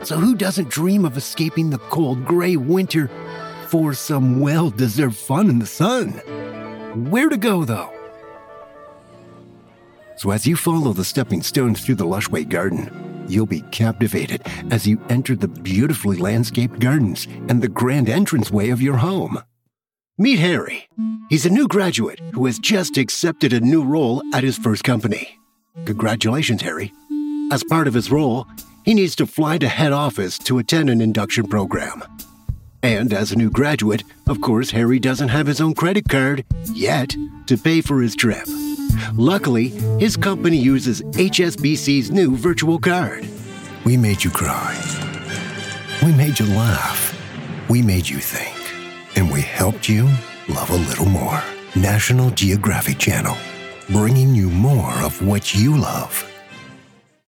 Commercial Demo
canadian gen-american
Middle Aged